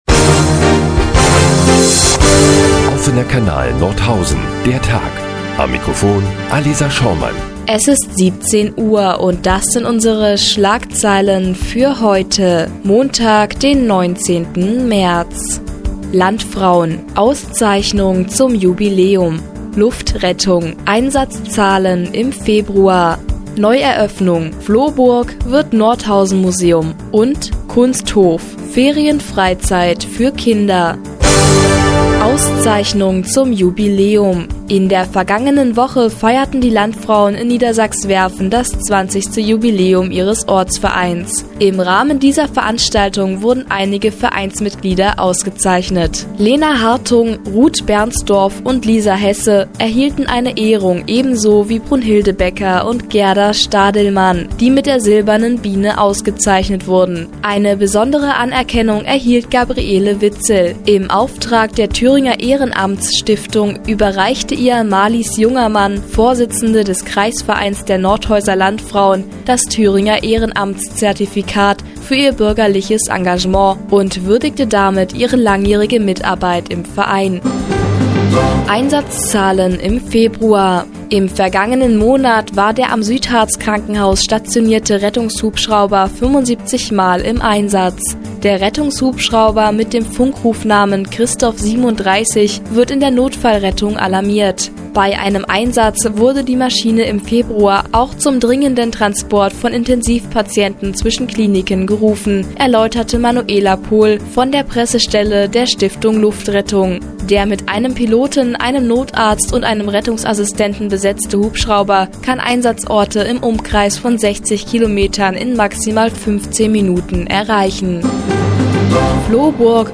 Die tägliche Nachrichtensendung des OKN ist nun auch in der nnz zu hören.